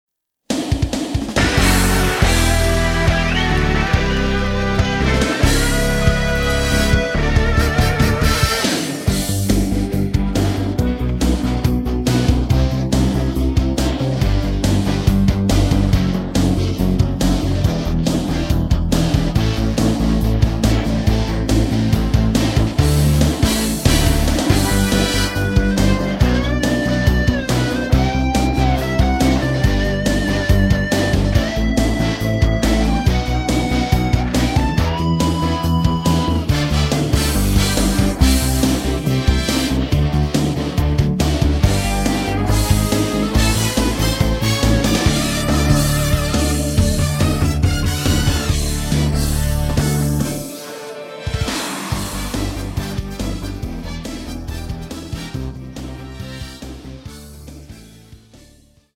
음정 원키 2:48
장르 가요 구분 Voice Cut
Voice Cut MR은 원곡에서 메인보컬만 제거한 버전입니다.